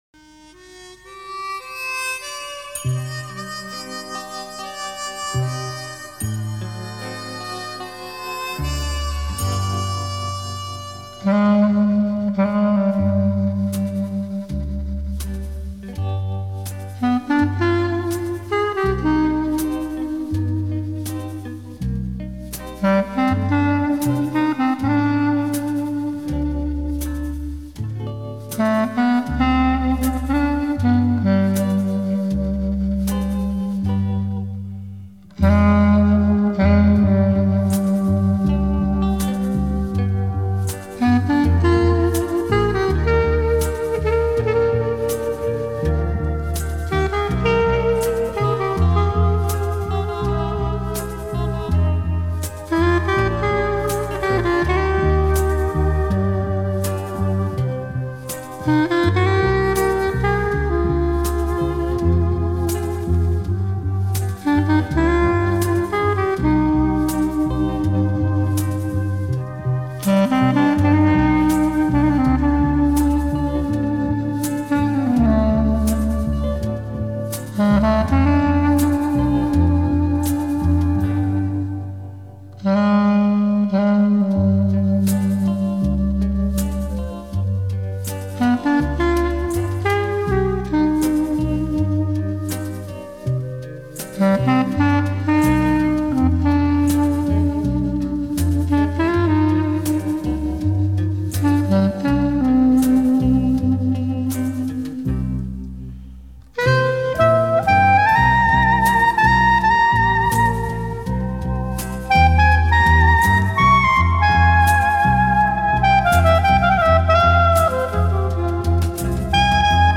Саксофон Маленький Цветок